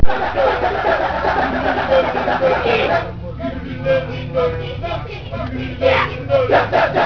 The Kecak dance with sound
Arma Museum, Ubud, Bali, Indonesia